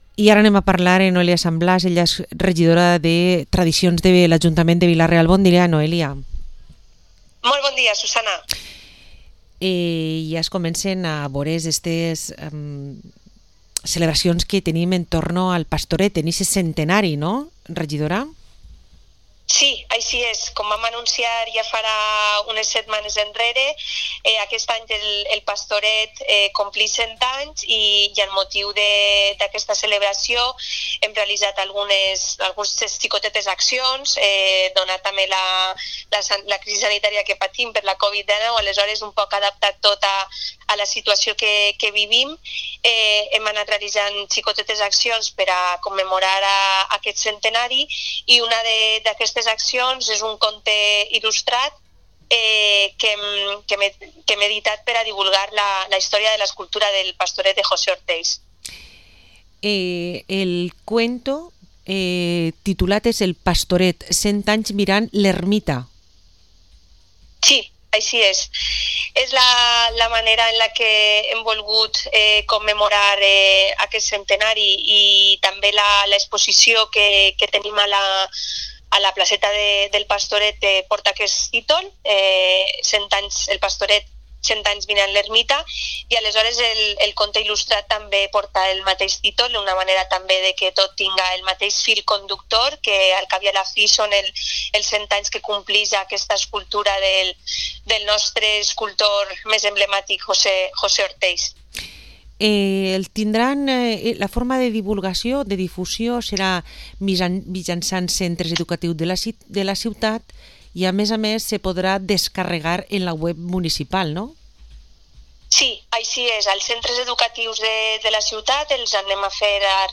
Entrevista a Noelia San Blas, Concejala de Tradicions en el Ayuntamiento de Vila-real